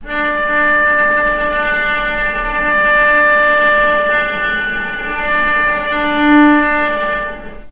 vc_sp-D4-mf.AIF